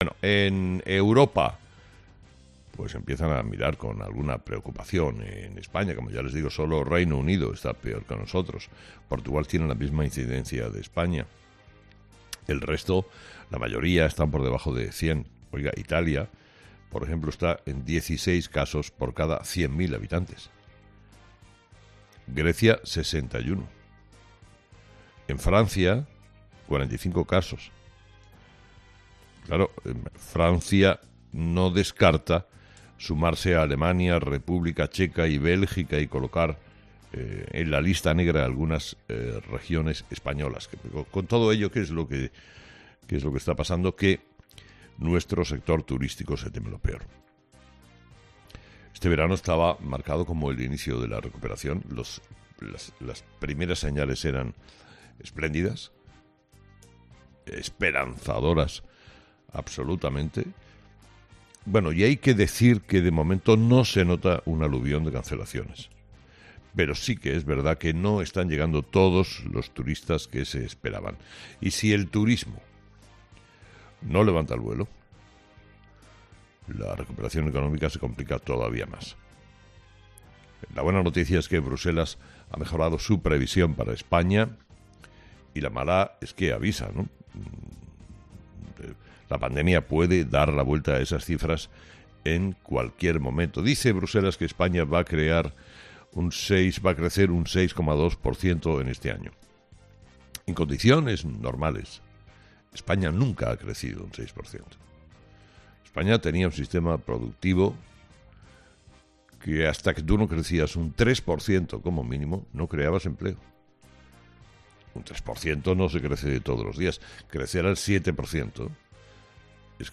Carlos Herrera, director y presentador de 'Herrera en COPE', ha comenzado el programa de este jueves analizando las principales claves de la jornada, que pasan por la subida de la incidencia de coronavirus y las medidas restrictivas que están tomando las Comunidades Autónomas.